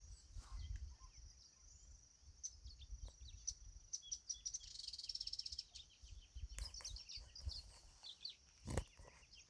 Sooty Tyrannulet (Serpophaga nigricans)
Location or protected area: Mburucuyá
Condition: Wild
Certainty: Observed, Recorded vocal